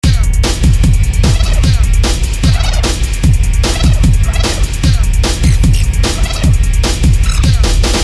描述：这是一个带有特效、人声和鼓声的样本
Tag: 150 bpm Drum And Bass Loops Drum Loops 1.35 MB wav Key : Unknown